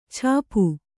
♪ chāpu